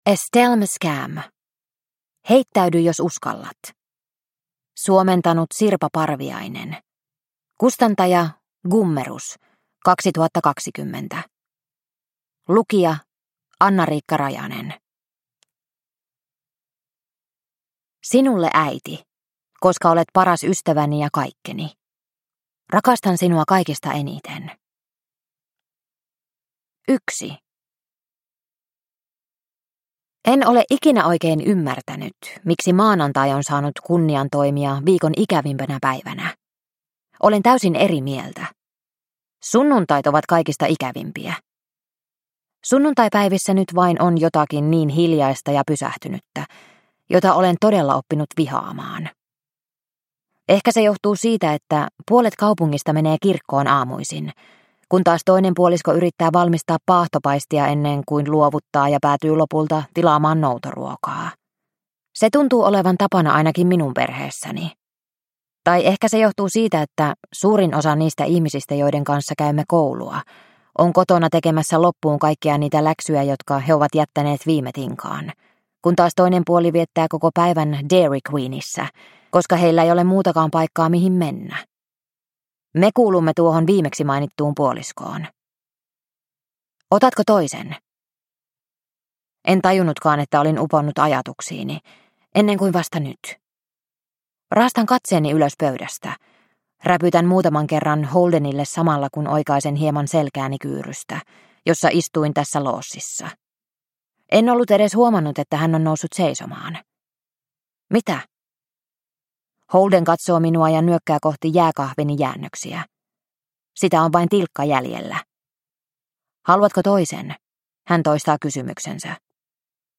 Heittäydy, jos uskallat – Ljudbok – Laddas ner